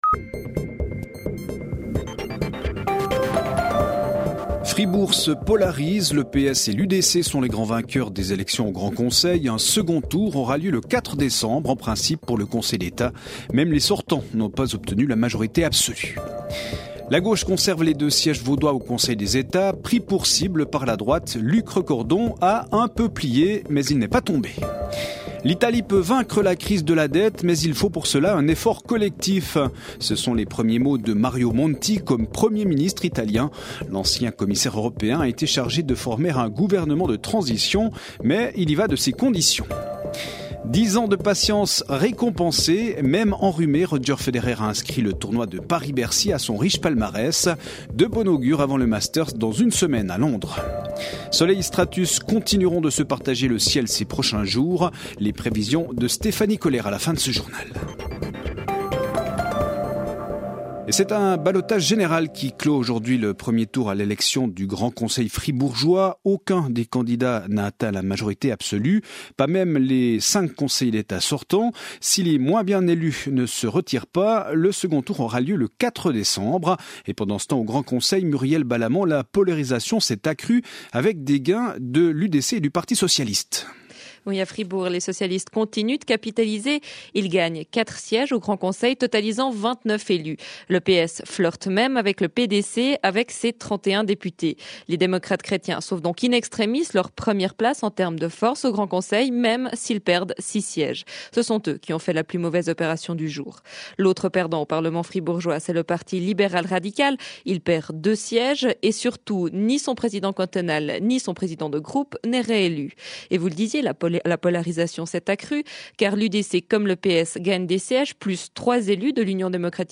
Chaque soir, la rédaction vous offre une synthèse de l’actualité du jour et des dernières informations politiques, économiques et sportives d’ici et d’ailleurs